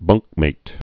(bŭngkmāt)